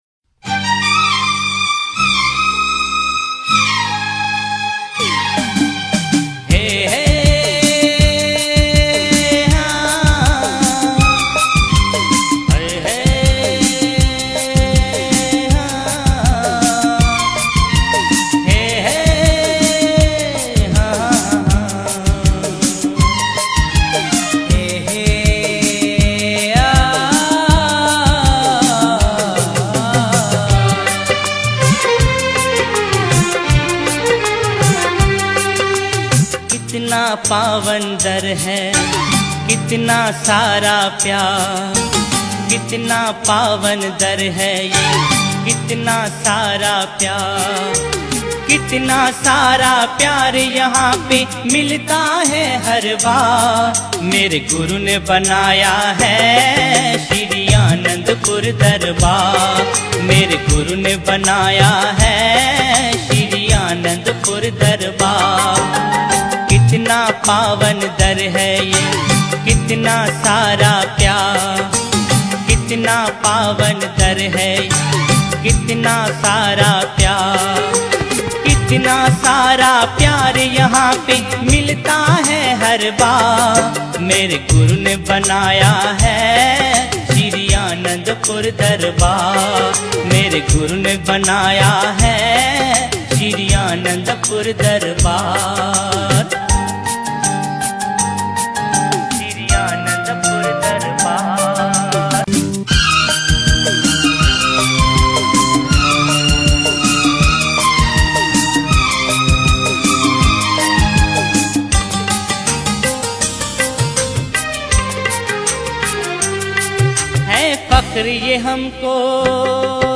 Bhajan 8- Kitna Pawan Dar hai